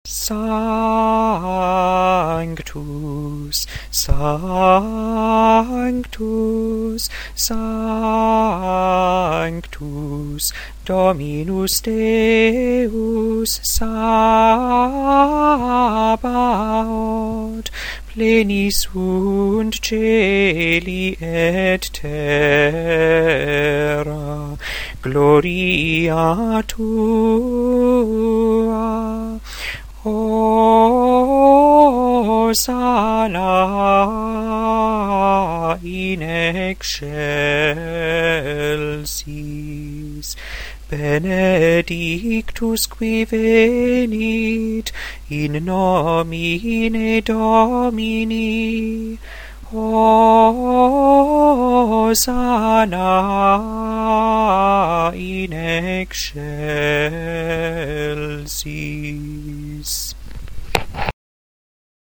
2. Gregorian Chant